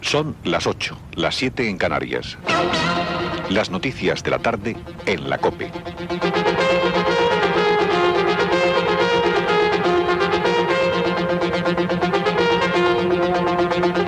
Hora i inici del programa Gènere radiofònic Informatiu